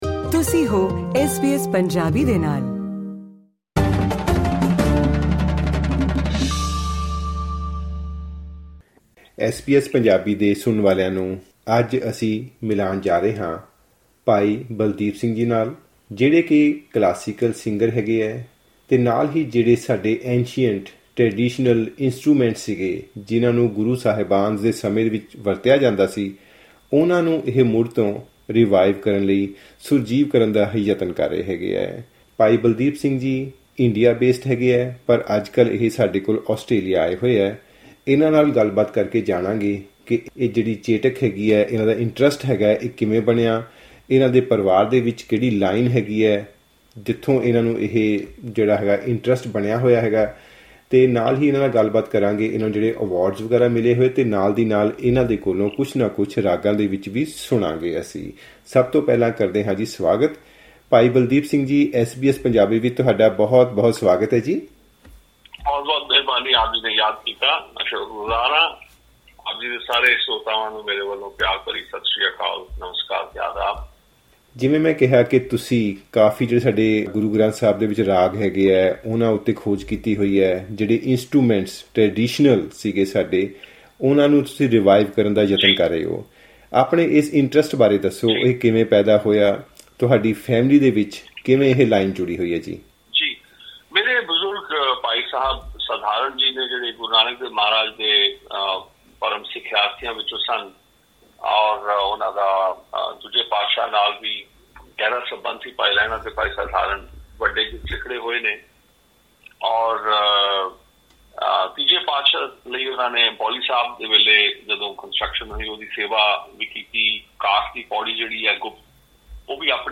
ਐਸ ਬੀ ਐਸ ਪੰਜਾਬੀ ਨਾਲ ਗਲ ਕਰਦਿਆਂ ਕੁੱਝ ਅਜਿਹੇ ਕਾਰਨ ਦੱਸੇ ਜਿਹਨਾਂ ਕਰਕੇ ਅਜੋਕੇ ਧਾਰਮਿਕ ਅਤੇ ਸਮਾਜਕ ਗਾਇਕ ਤੰਤੀ ਸਾਜਾਂ ਅਤੇ ਪੁਰਾਤਨ ਰਾਗਾਂ ਆਦਿ ਤੋਂ ਕਿਉਂ ਦੂਰ ਹੋ ਰਹੇ ਹਨ।